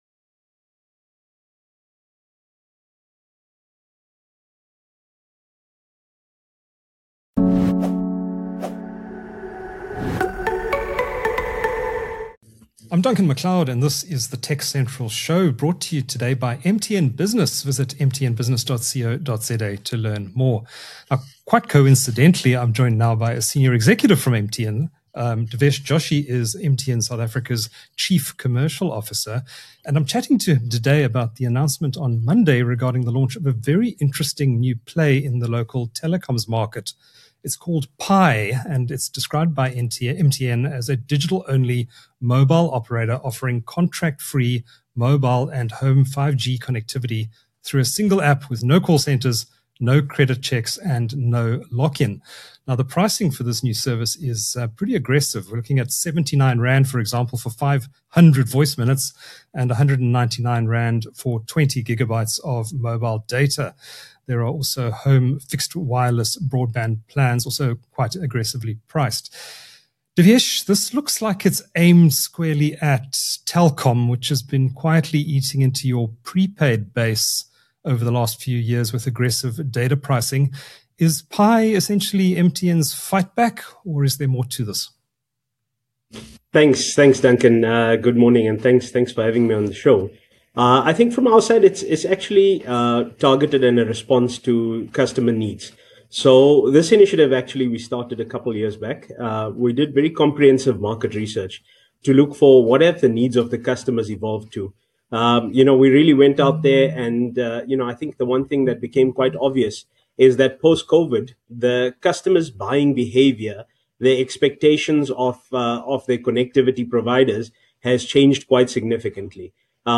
Don’t miss the conversation!